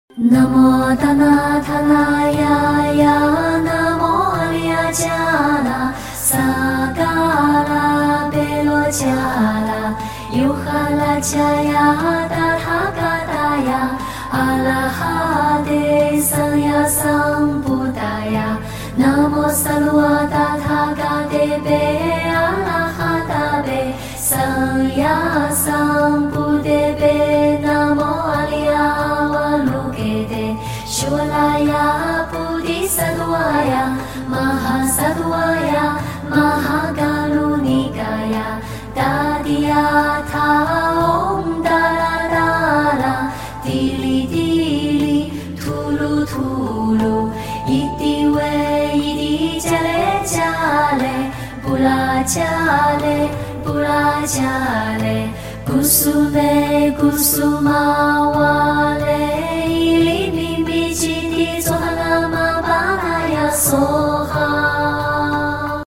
Healing music Great Compassion sound effects free download